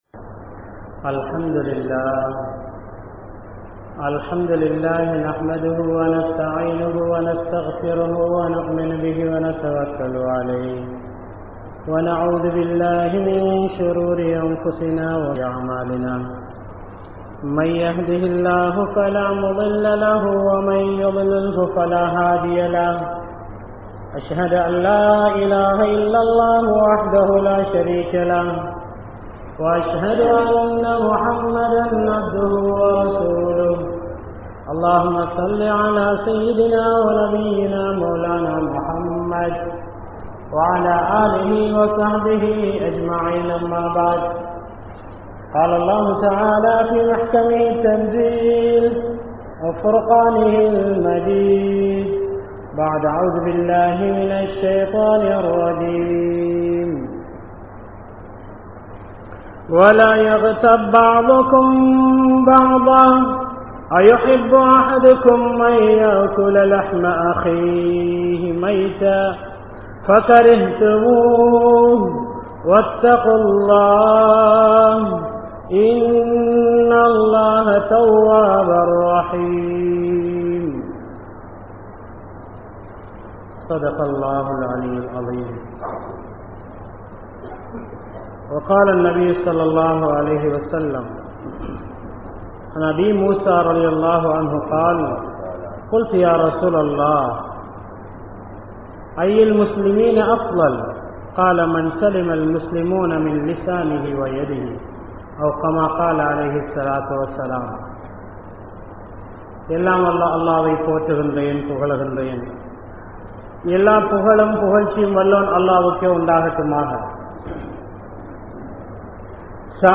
Allahvin Padaipuhal (Allah\'s creations) | Audio Bayans | All Ceylon Muslim Youth Community | Addalaichenai
Ansari Jumua Masjith